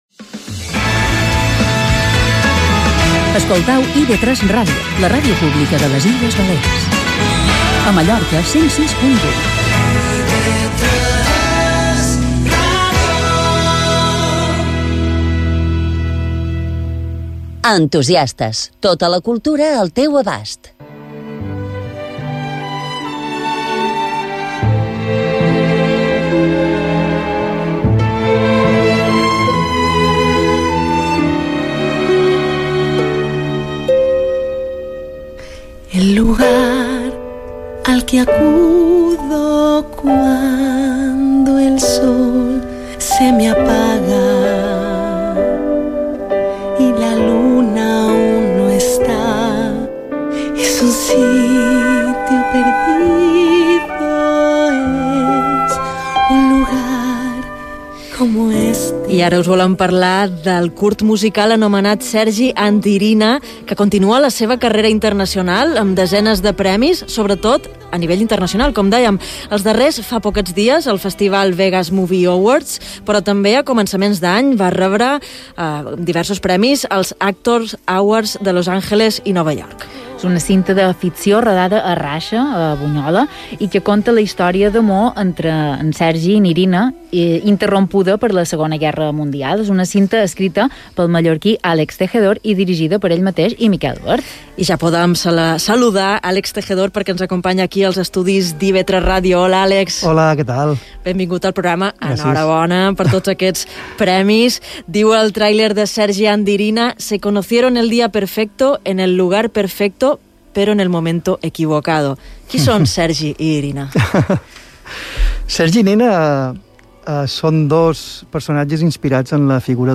Radio announcers
Interview